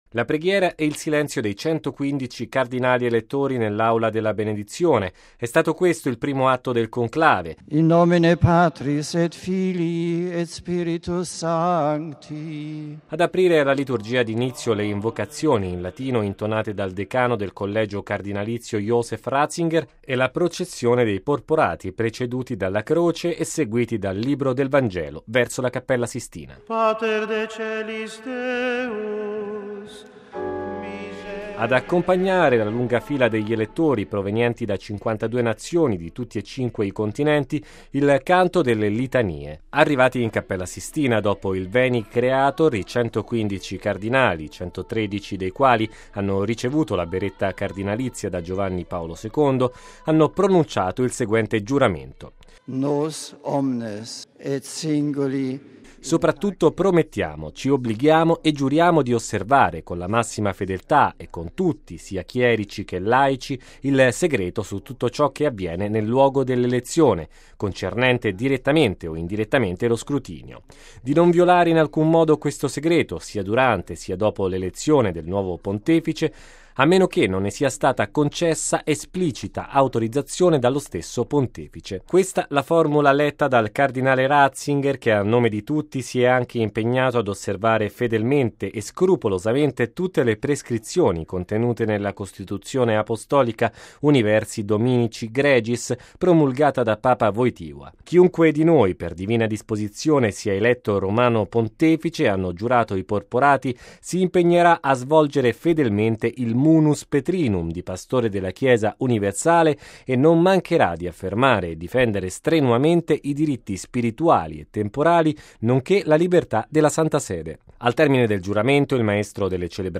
Ad aprire la liturgia di inizio le invocazioni in latino intonate dal decano del collegio cardinalizio Joseph Ratzinger e la processione dei porporati, preceduti dalla Croce e seguiti dal Libro del Vangelo, verso la Cappella Sistina. Ad accompagnare la lunga fila degli elettori provenienti da 52 nazioni di tutti e 5 i continenti il canto delle Litanie.